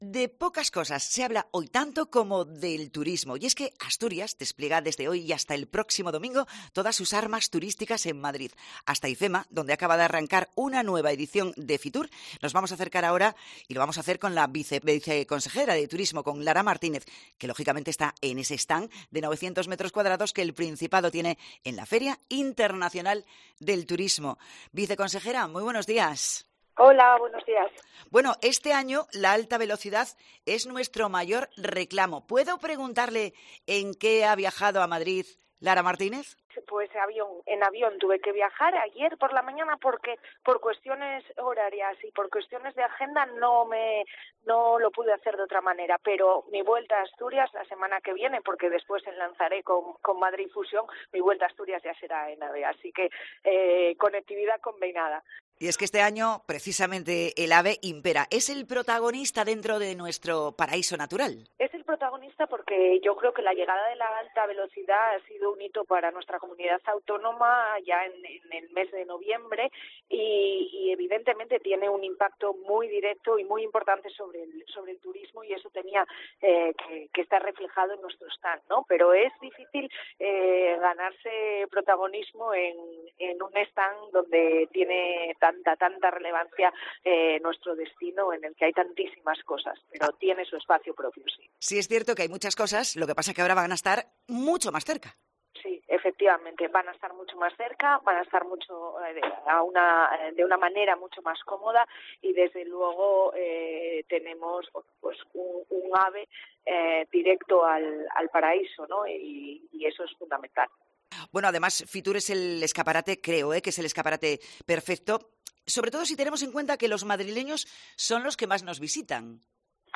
FITUR 2024: Entrevista a Lara Martínez, viceconsejera de Turismo del Principado